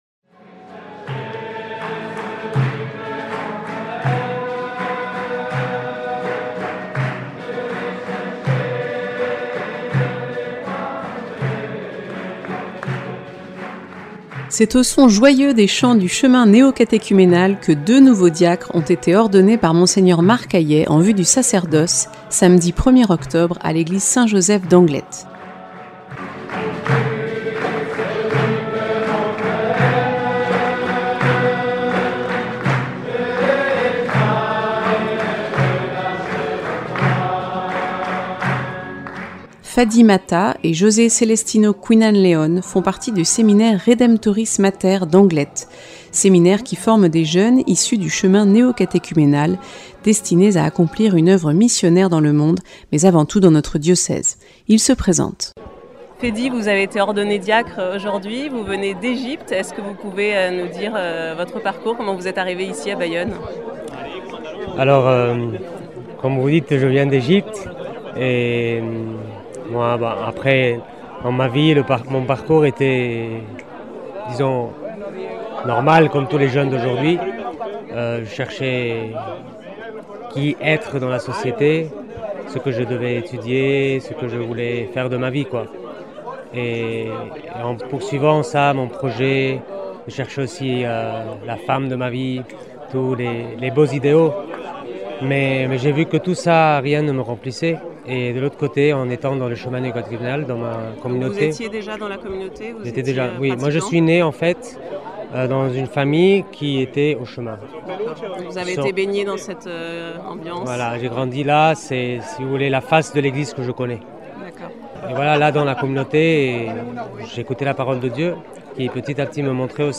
Reportage réalisé le 1er octobre 2022 en l’église Saint-Joseph à Anglet.